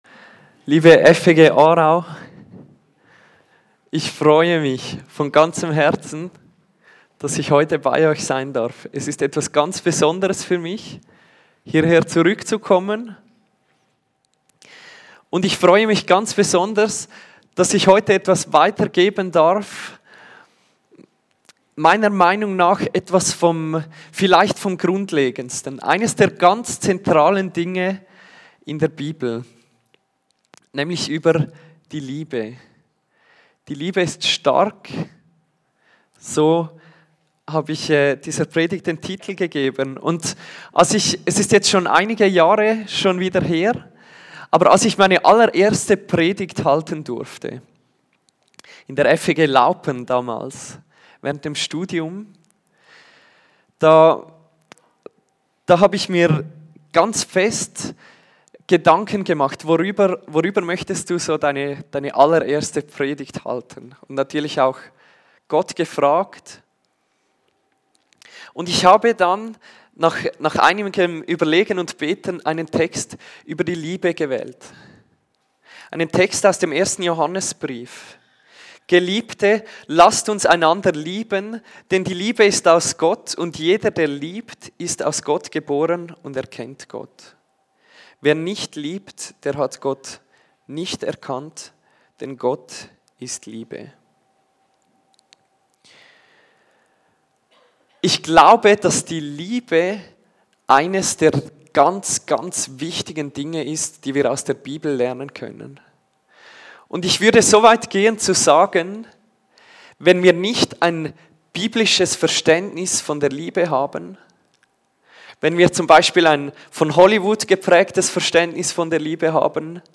Kategorie: Predigt